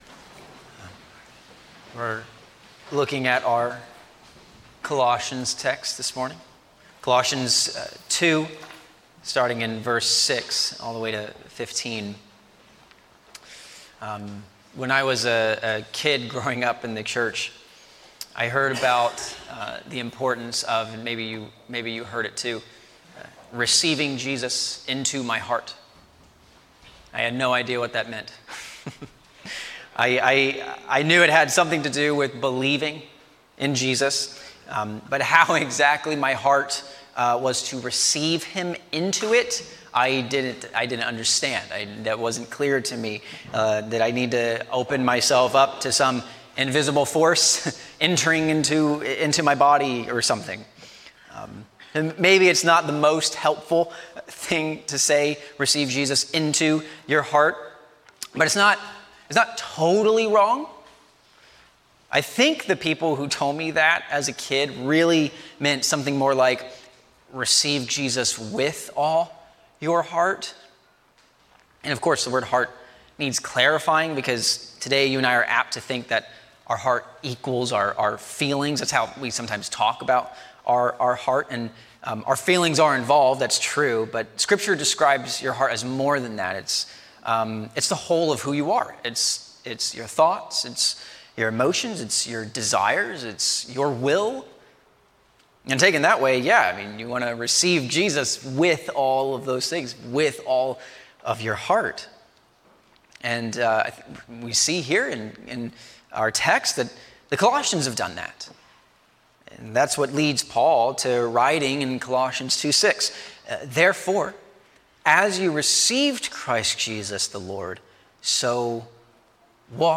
A sermon on Colossians 2:6-15